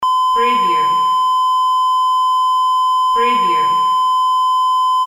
Beep sound effect .wav #11
Description: A simple beep sound effect
Keywords: beep, beeps, beeping, single, simple, basic, interface, flash, game, multimedia, animation, menu, button, navigation, electronic, censor
beep-preview-11.mp3